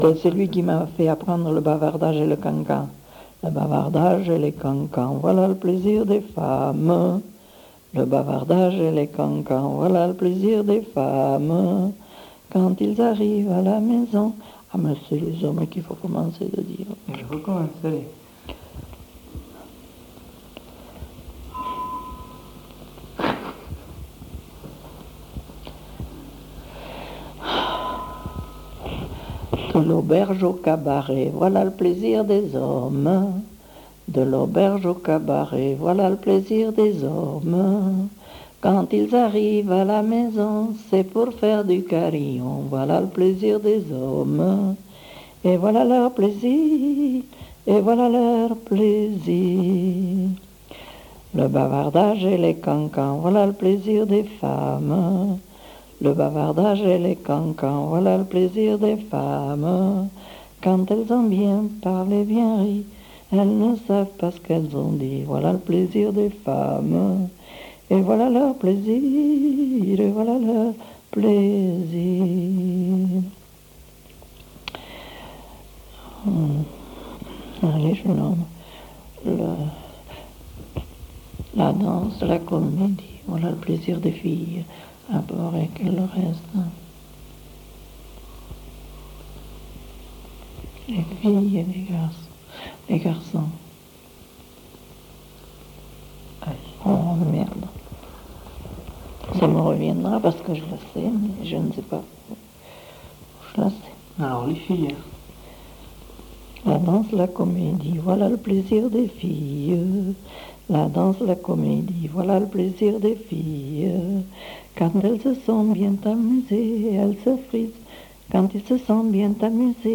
Lieu : Saint-Amans-des-Cots
Genre : chant
Effectif : 1
Type de voix : voix de femme
Production du son : chanté
Description de l'item : version ; 6 c. ; refr.